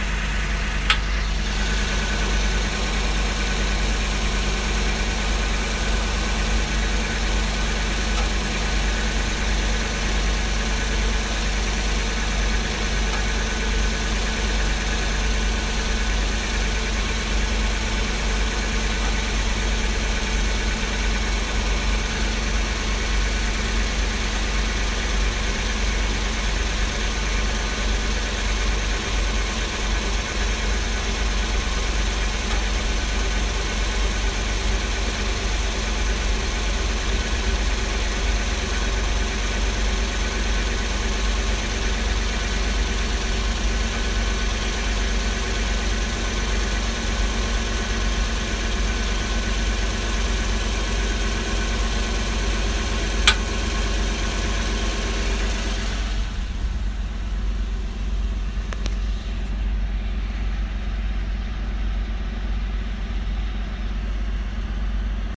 Что-то щелкает на "холодном" двигателе
Звук очень похож на подключение компрессора кондиционера, но тише.
Подскажите пожалуйста, что это за звук на записи (прилагаю) на 8, 13, 32, 53 секундах и правильно ли у меня работает кондиционер с подкидывая обороты, так, что толкает машину вперед.
Хотя звуков 2 вида, один явно подключает кондиционер, а второй при начале кручения шкифа.
Ну, конечно, запись несколько искажает звук но очень похоже.